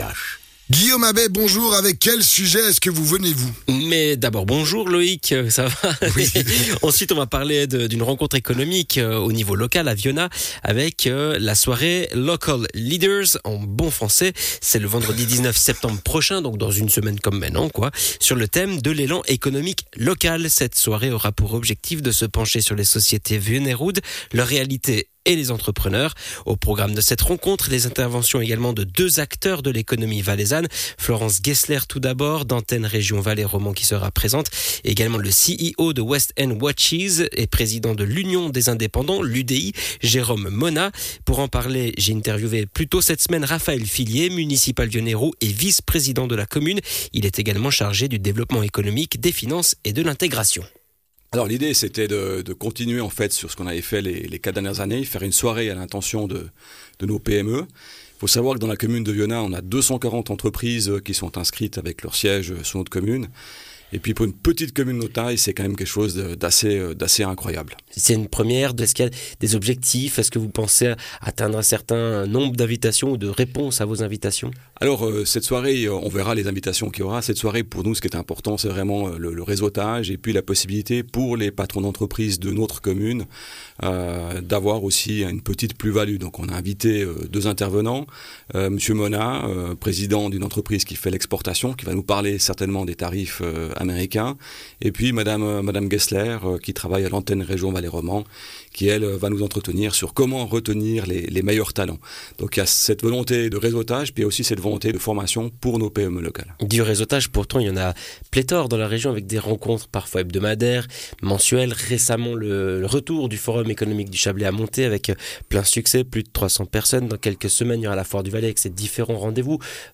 Intervenant(e) : Raphaël Filliez - Vice-Prés. de la Commune et Municipal